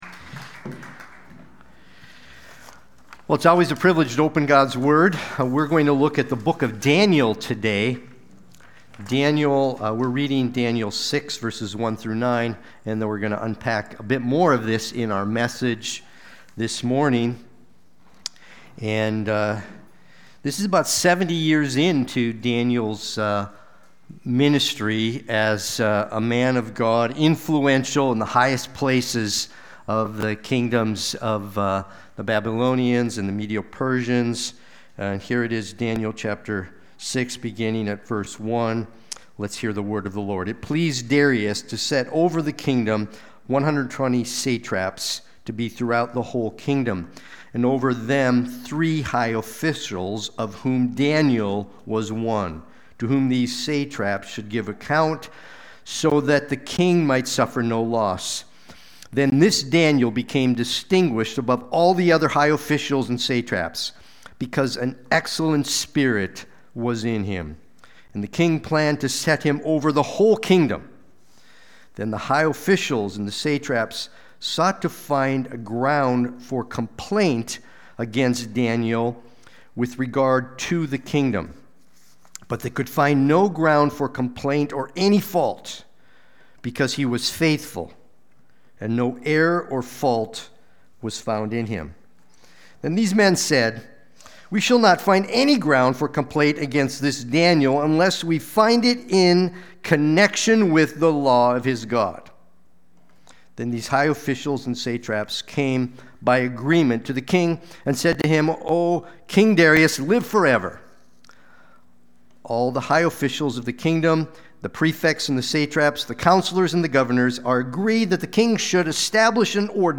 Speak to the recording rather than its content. Sunday-Worship-main-62925.mp3